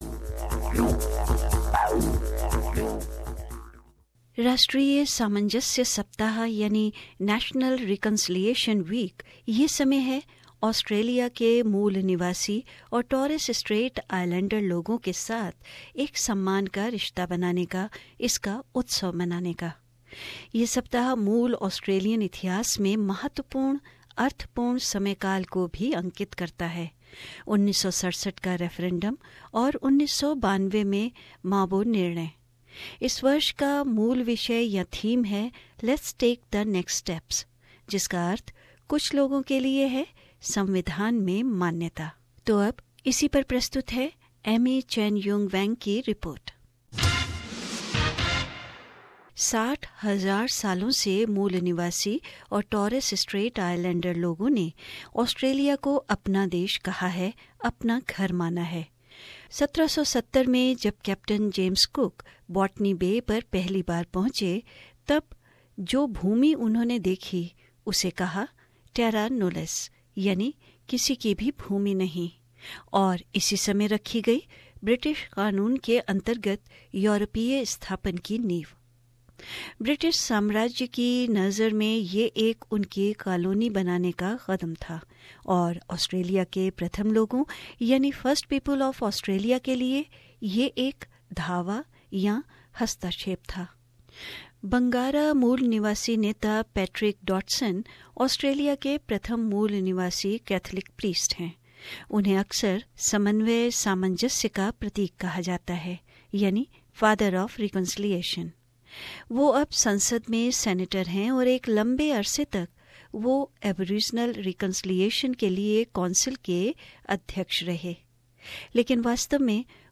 SBS Hindi